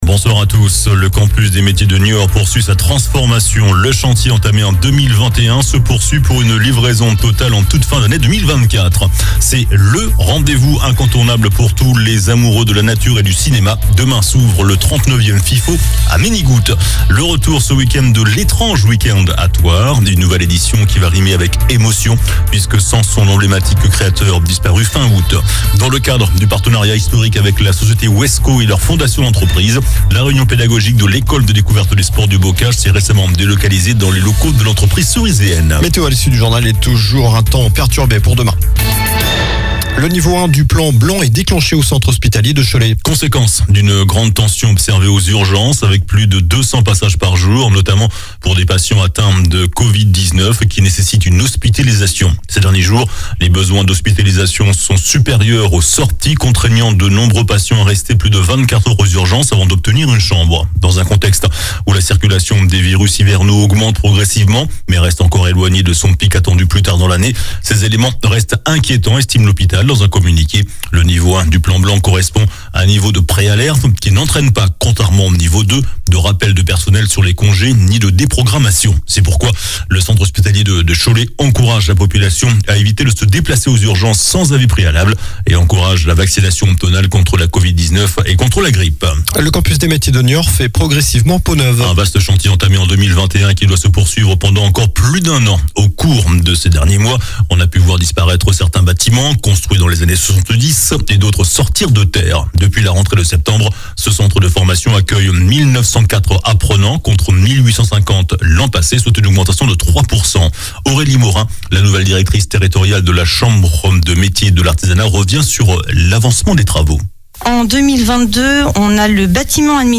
Journal du jeudi 26 octobre (soir)